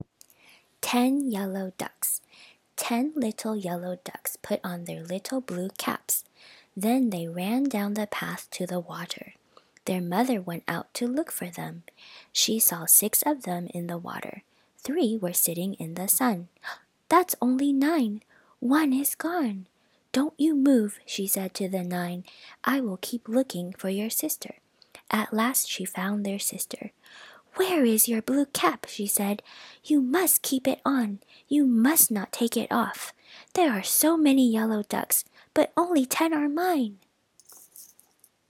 ■普通の速さ